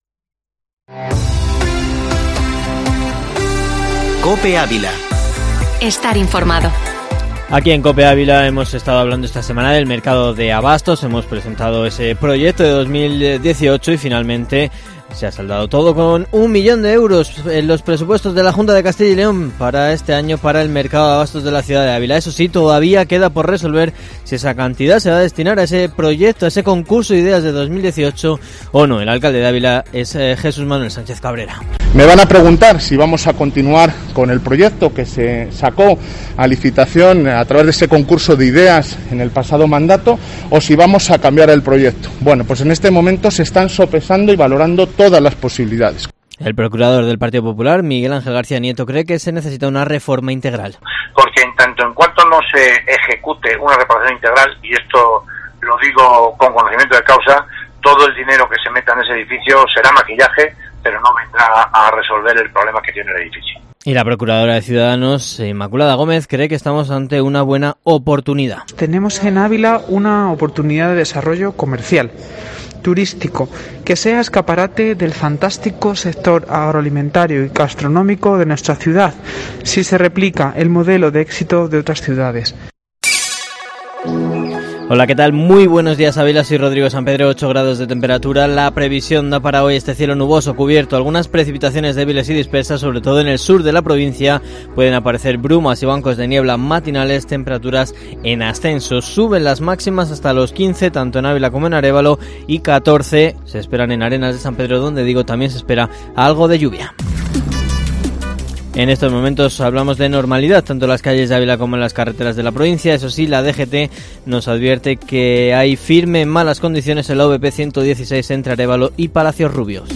Informativo matinal Herrera en COPE Ávila 11/02/2021